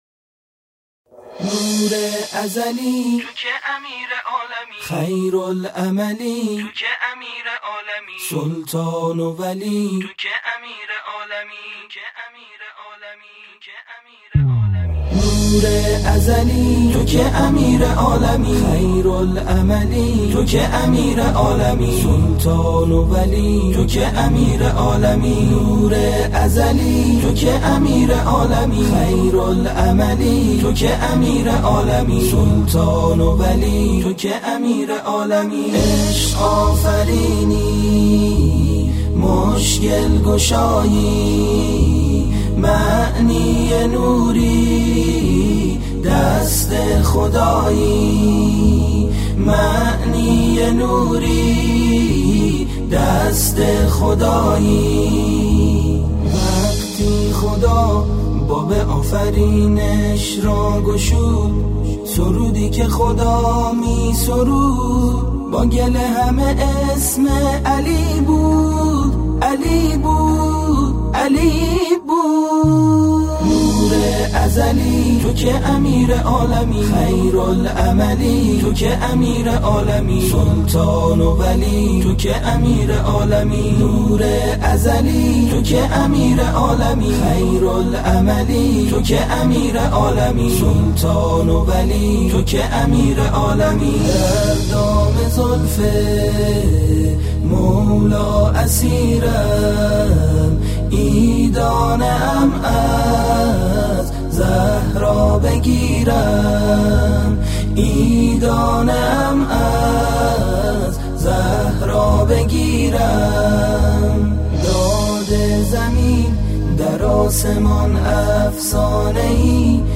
مولودی زیبا و دلنشین